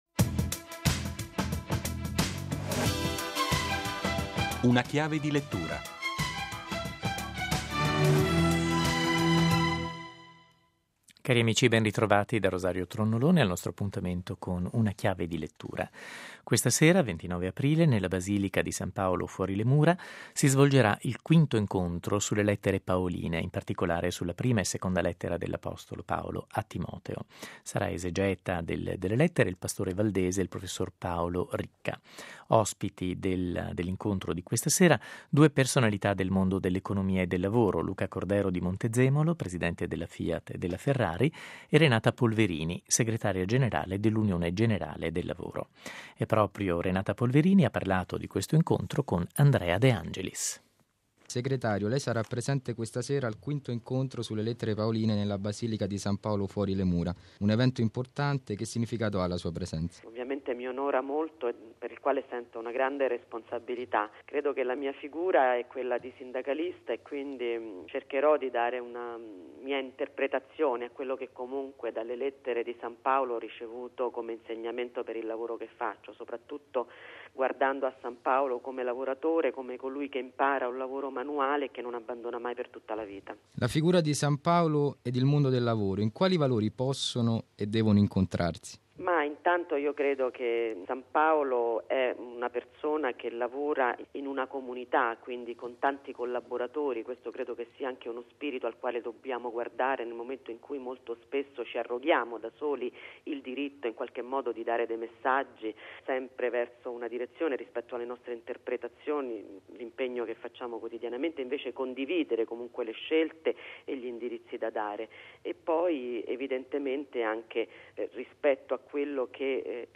Ma chi ha di più, dice, deve pensare all'intera comunità. Nella Basilica di San Paolo, a Roma, proseguono gli incontri dedicati all'anno paolino.